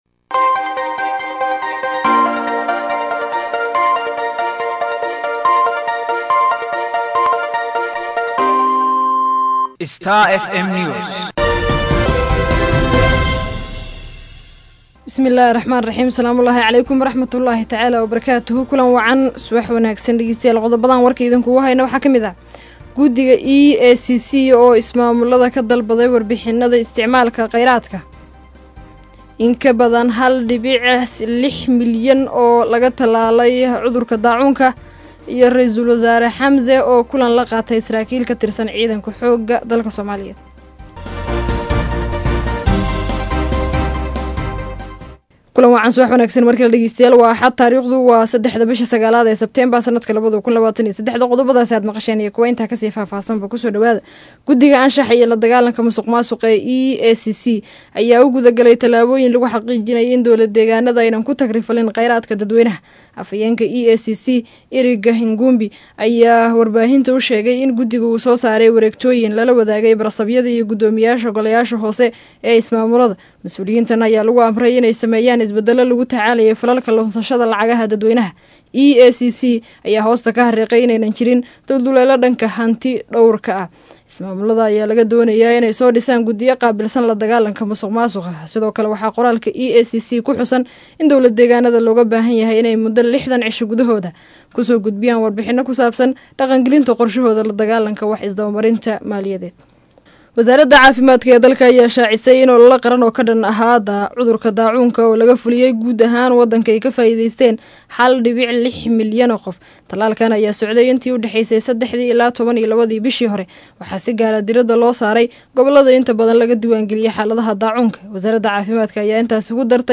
DHAGEYSO:WARKA SUBAXNIMO EE IDAACADDA STAR FM